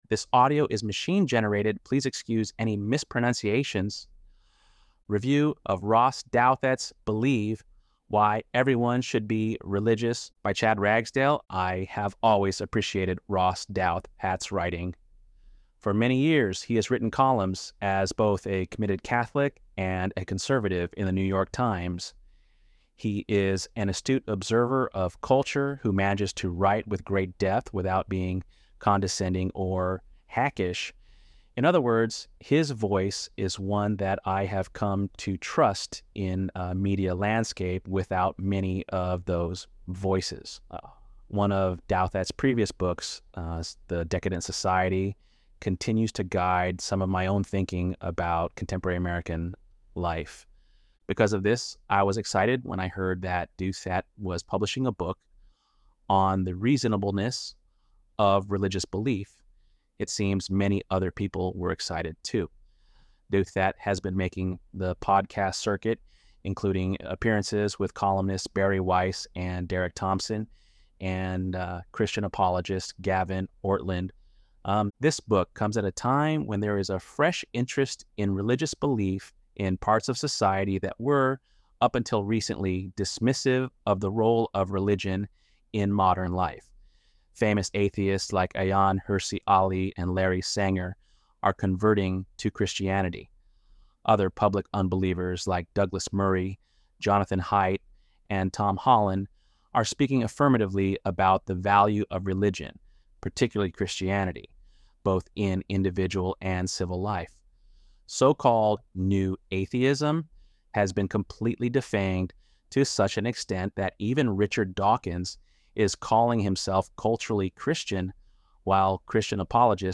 ElevenLabs_5.1_Douthat.mp3